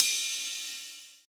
D2 RIDE-06-L.wav